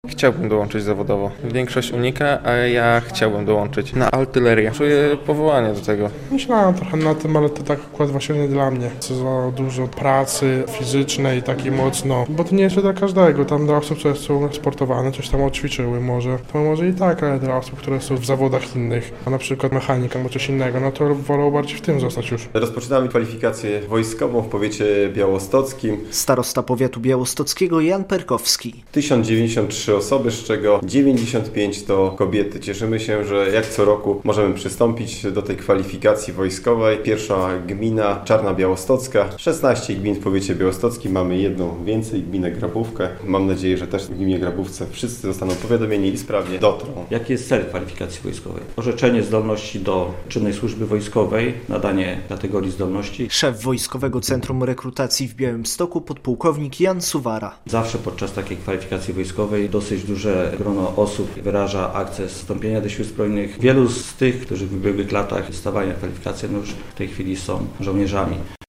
Kwalifikacja wojskowa w Białymstoku - relacja